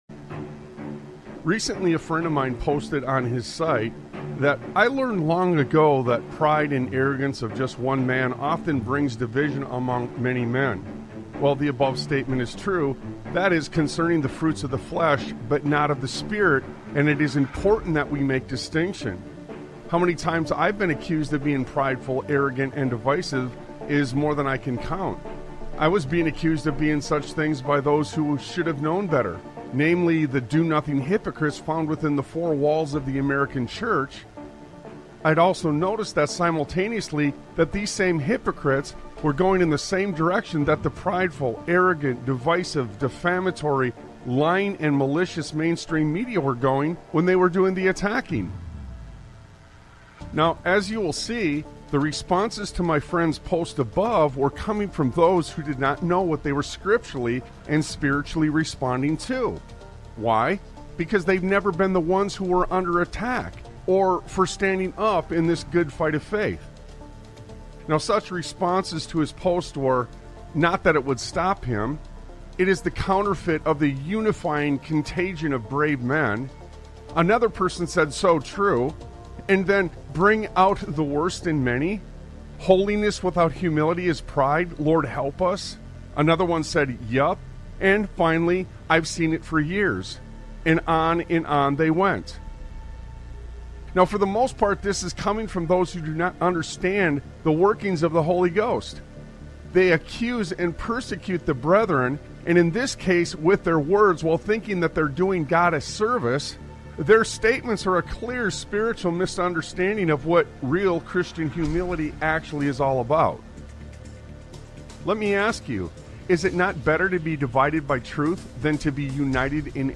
Talk Show Episode, Audio Podcast, Sons of Liberty Radio and He Said It... on , show guests , about He Said It, categorized as Education,History,Military,News,Politics & Government,Religion,Christianity,Society and Culture,Theory & Conspiracy